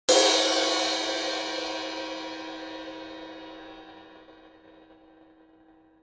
ZILDJIAN ( ジルジャン ) >A ZILDJIAN MEDIUM CRASH 16
ハイピッチで程よくコントロールされたサスティン。